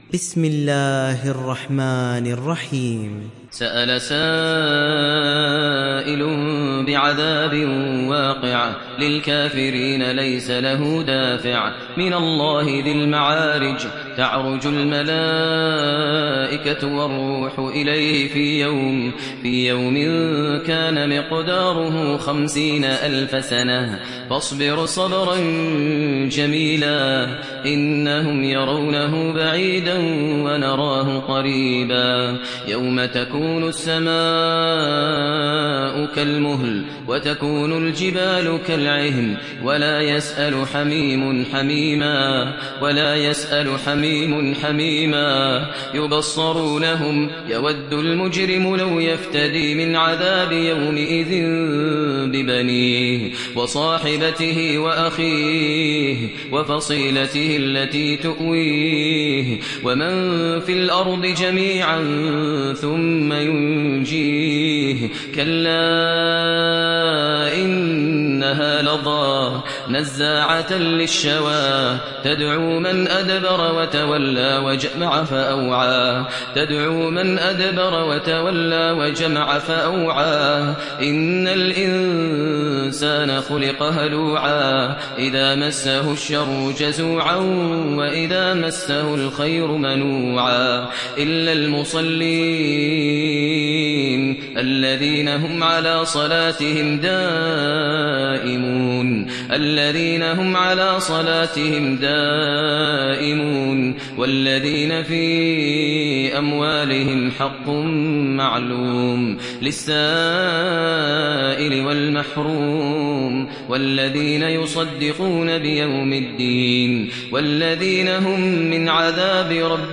دانلود سوره المعارج mp3 ماهر المعيقلي روایت حفص از عاصم, قرآن را دانلود کنید و گوش کن mp3 ، لینک مستقیم کامل